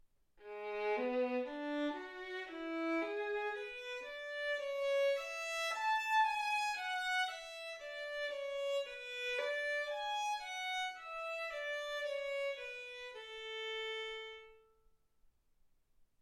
Hegedű etűdök Kategóriák Klasszikus zene Felvétel hossza 00:16 Felvétel dátuma 2025. december 8.